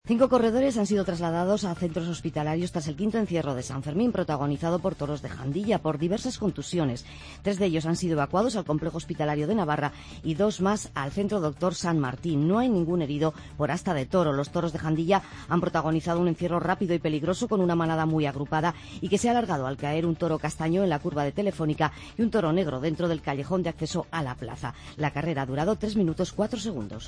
Crónica 5º encierro de San Fermín